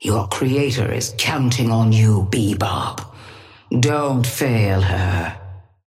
Sapphire Flame voice line - Your creator is counting on you, Bebop. Don't fail her.
Patron_female_ally_bebop_start_07.mp3